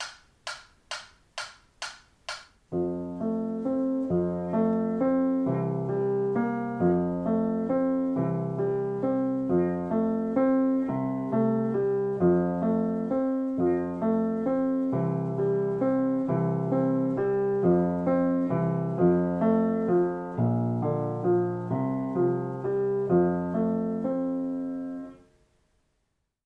Leichte Klavierstücke für vier Hände.